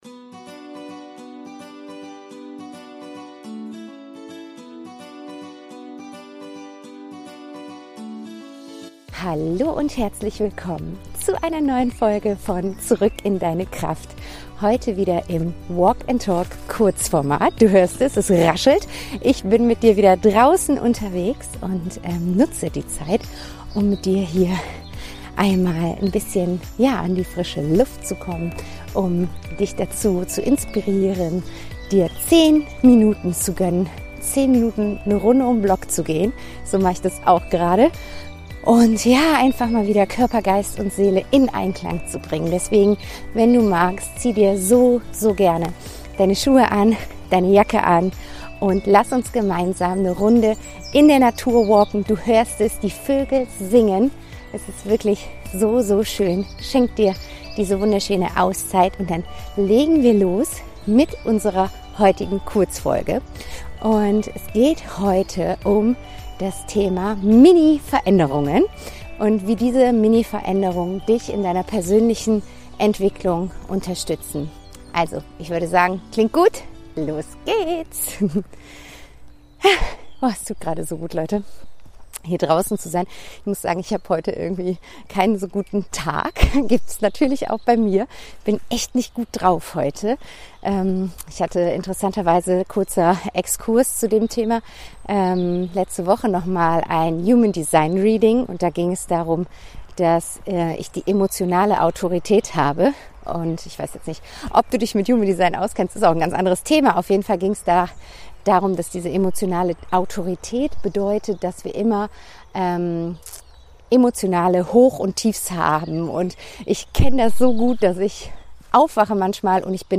In diesen Folgen nehme ich dich mit auf einen Spaziergang. Beim Gehen teile ich spontane Gedanken, ehrliche Impulse und leise Fragen aus dem Moment heraus – unperfekt in der Tonqualität, dafür nah, authentisch und mitten aus dem Leben.